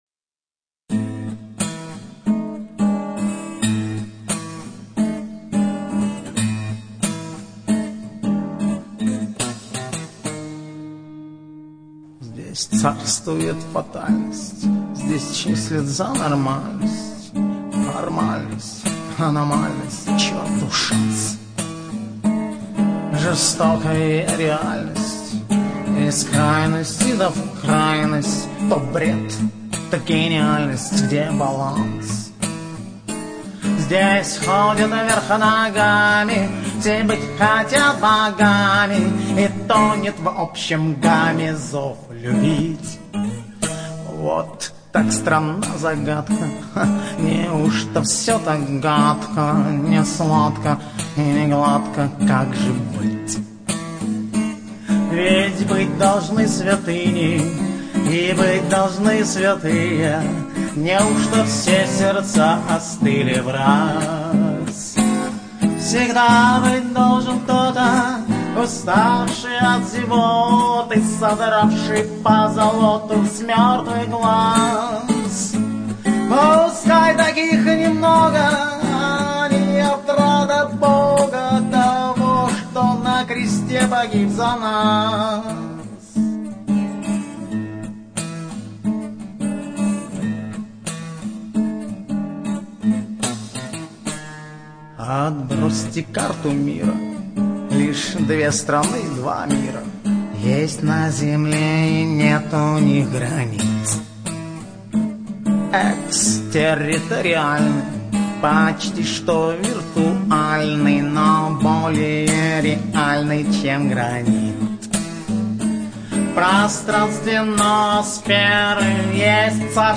Прекрасный выразительный певец
с крутой профессиональной аранжировкой